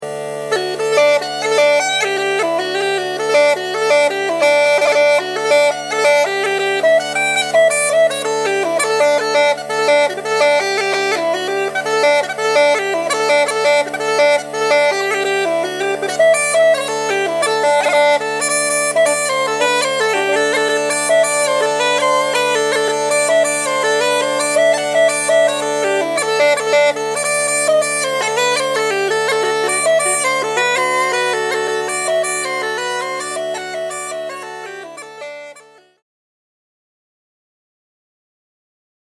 Uilleann Pipes - Easy Online Lessons - Online Academy of Irish Music
Uilleann-Pipes.mp3